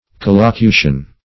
Search Result for " collocution" : The Collaborative International Dictionary of English v.0.48: Collocution \Col`lo*cu"tion\, n. [L. collocutio, fr. colloqui, -locutum, to converse; col- + loqui to speak.